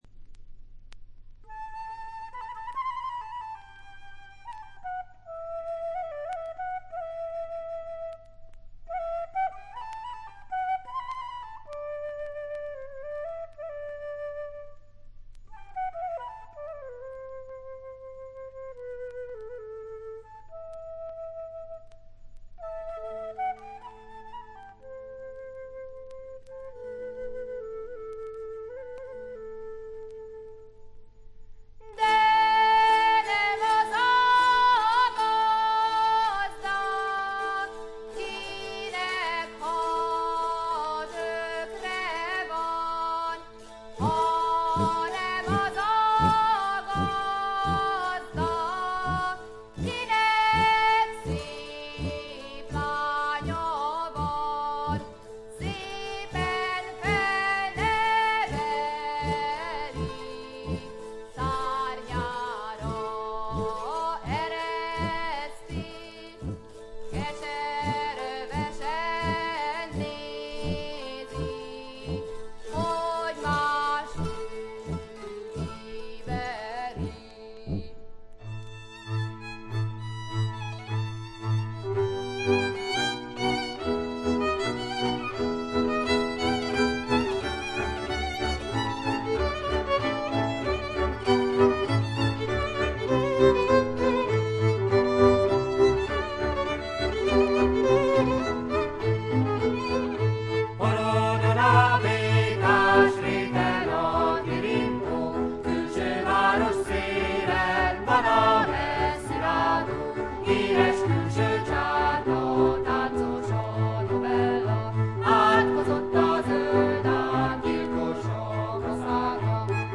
見た目よりちょっと劣り、細かなチリプチや散発的なプツ音は聴かれます。
ハンガリーの男女混成7−8人のトラッド・グループ。
エキゾチックな演奏に力強い女性ヴォーカルが響き合って得も言われぬ世界を描き出しています。
試聴曲は現品からの取り込み音源です。